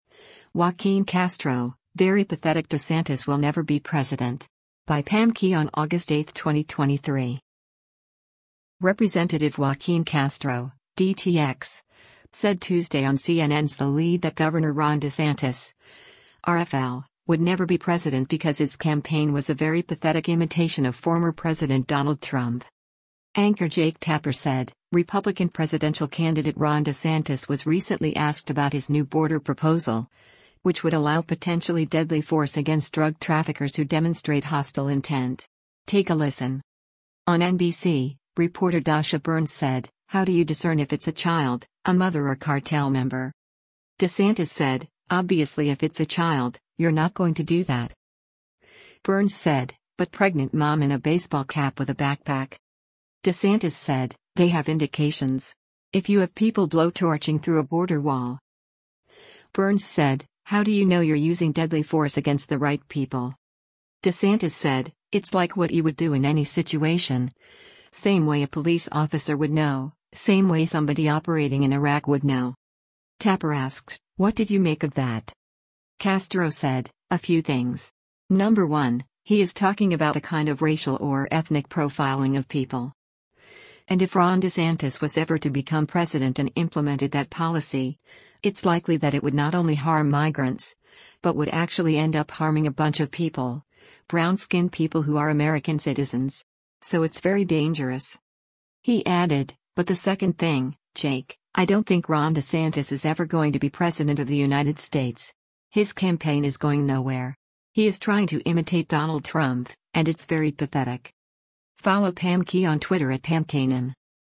Representative Joaquin Castro (D-TX) said Tuesday on CNN’s “The Lead” that Governor Ron DeSantis (R-FL) would never be president because his campaign was a “very pathetic” imitation of former President Donald Trump.